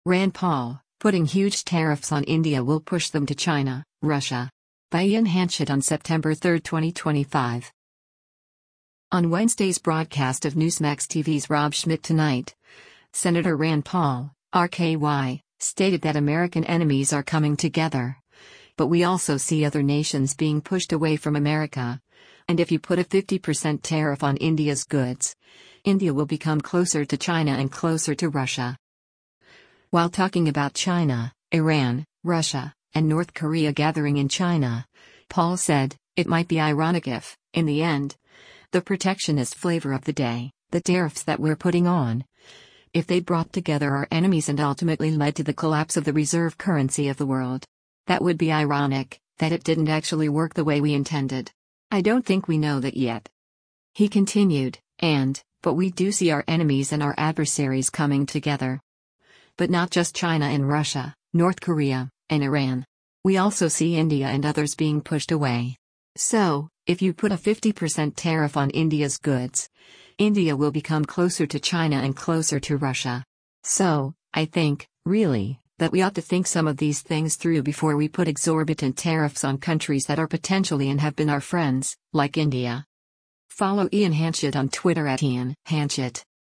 On Wednesday’s broadcast of Newsmax TV’s “Rob Schmitt Tonight,” Sen. Rand Paul (R-KY) stated that American enemies are coming together, but we also see other nations being pushed away from America, and “if you put a 50% tariff on India’s goods, India will become closer to China and closer to Russia.”